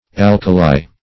Alkali \Al"ka*li\ (?; 277), n.; pl.